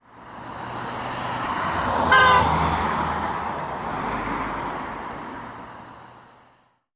Звуки дорожного движения
Гудящий звук автомобиля на перекрестке